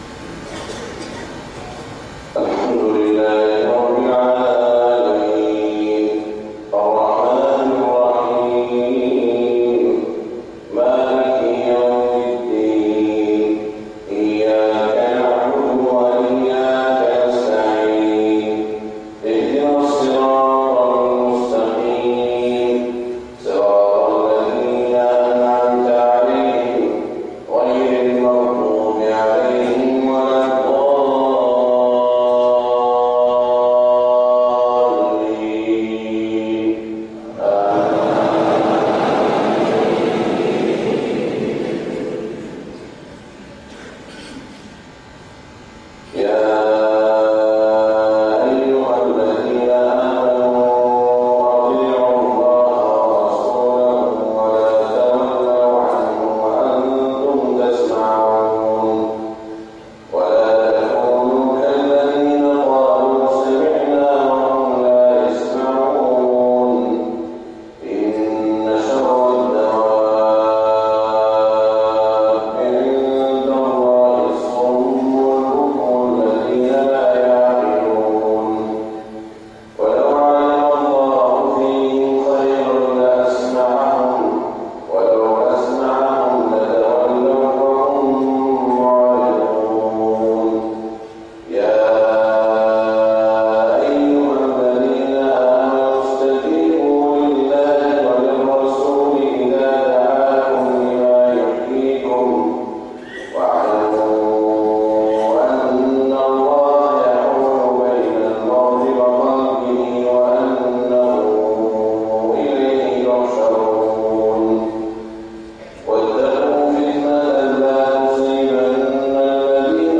صلاة الفجر 1433هـ من سورة الأنفال في الهند > زيارة الشيخ سعود الشريم لدولة الهند > تلاوات و جهود الشيخ سعود الشريم > المزيد - تلاوات الحرمين